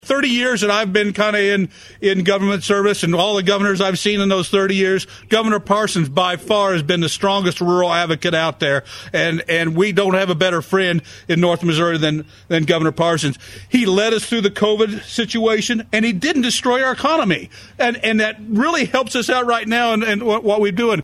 Several government officials attended the bill signing ceremony.